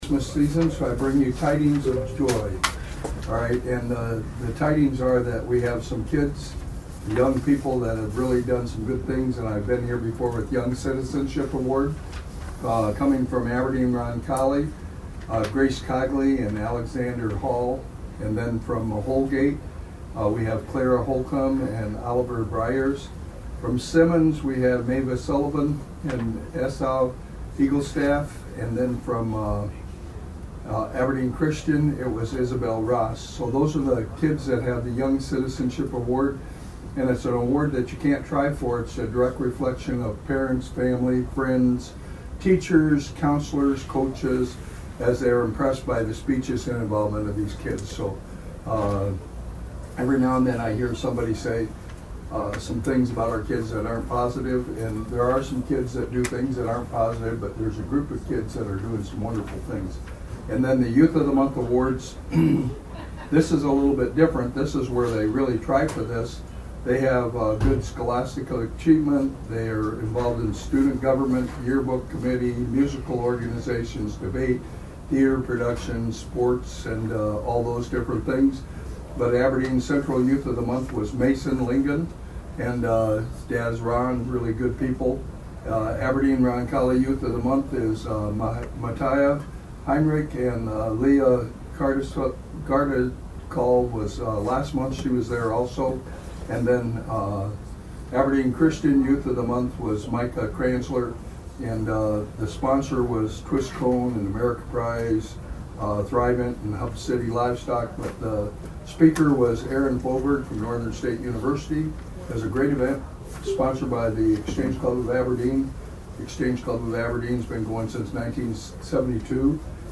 Senator-elect Carl Perry who is also a member of the Aberdeen Exchange Club announced it a both the Aberdeen City Council meeting Monday & Brown County Commission meeting Tuesday.(2:11)